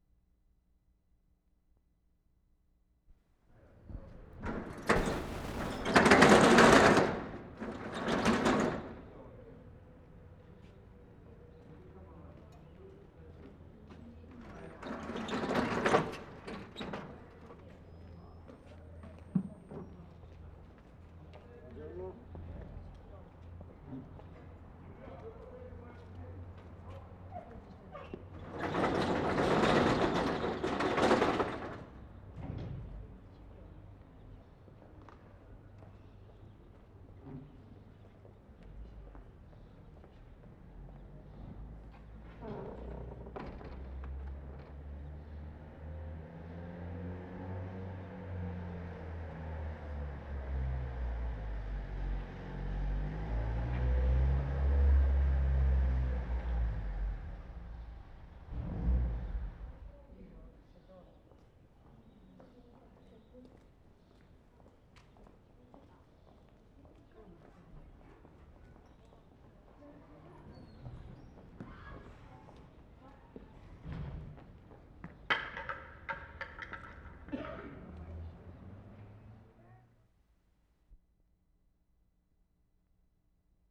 STORE-FRONT SHUTTERS opening on central square.
3. Note the reverberation.